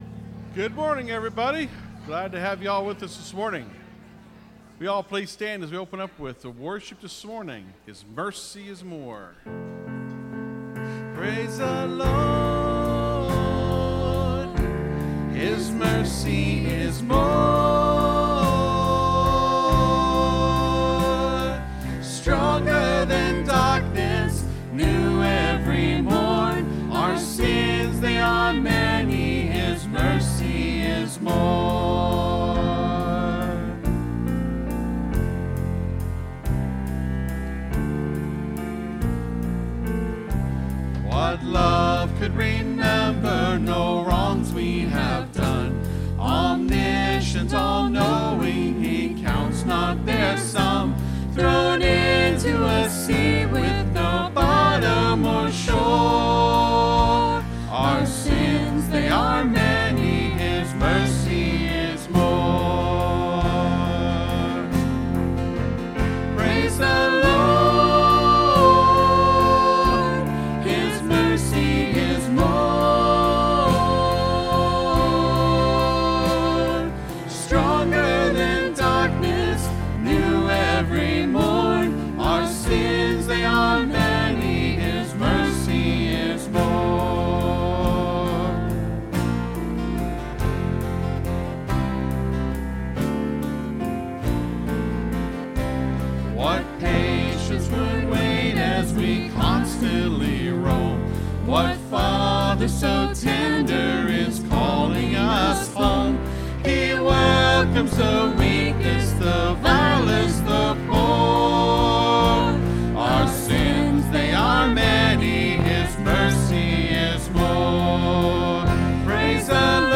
(Sermon starts at 27:45 in the recording).